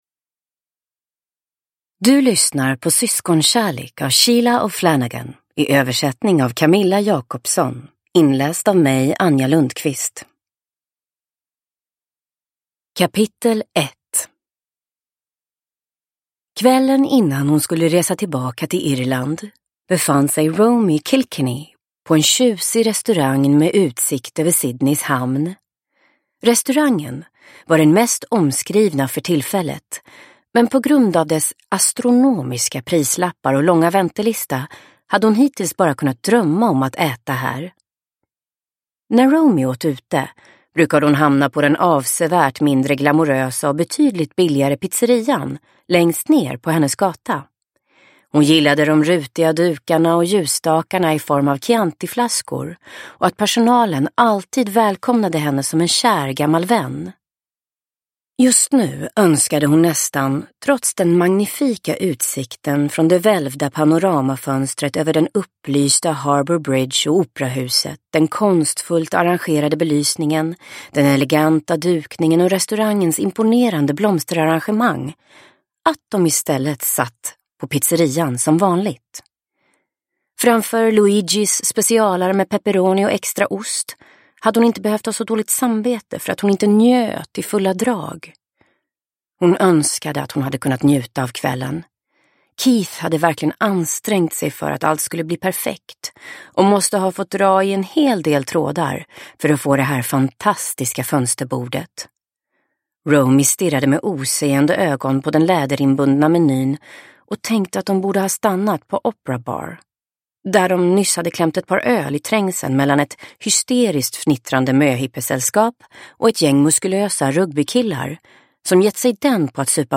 Syskonkärlek – Ljudbok – Laddas ner